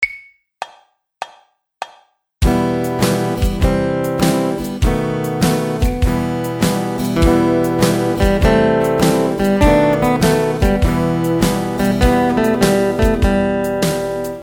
They both play a similar lick over two different sets of chord changes in the key of G major then in E minor. You will notice that the major one sounds like country music while the minor one will sound more like the blues.
E Minor Pentatonic Lick | Download
epent_lick.mp3